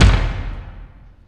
• High Floor Tom One Shot D# Key 4.wav
Royality free tome drum sample tuned to the D# note. Loudest frequency: 648Hz
high-floor-tom-one-shot-d-sharp-key-4-JQN.wav